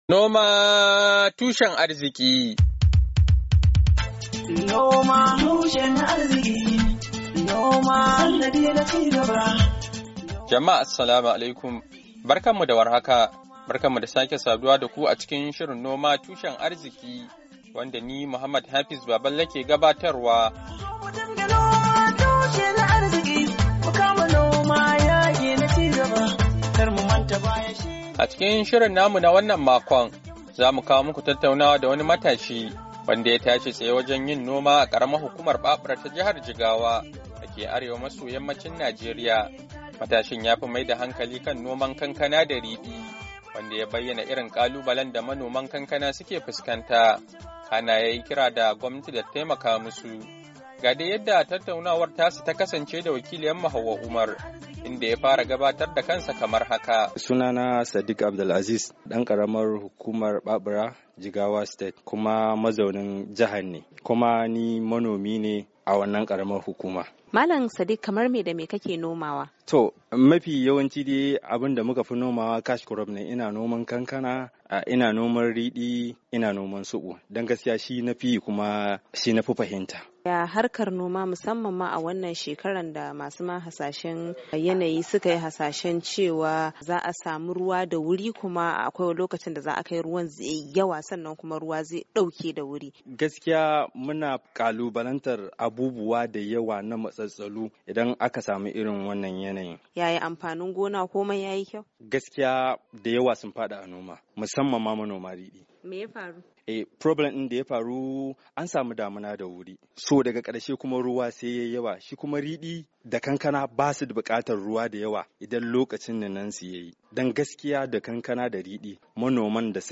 A cikin shirin mu na wannan makon, za mu kawo muku tattaunawa da wani matashi, wanda ya tashi tsaye wajan yin noma a karamar hukumar babura ta jihar Jigawa da ke arewa maso yammacin Najeriya.